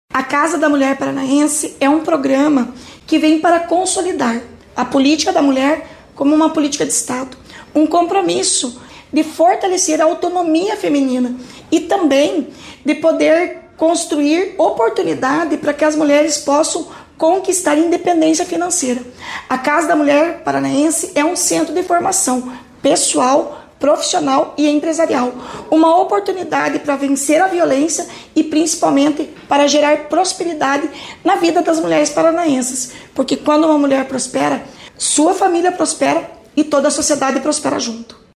A secretária da Mulher, Igualdade Racial e Pessoa Idosa, Leandre Dal Ponte, destacou que o modelo paranaense se diferencia do federal por seu caráter multifuncional, o que permite um atendimento mais abrangente.
SONORA-–-CASA-MULHER-PARANAENSE-1-SP.mp3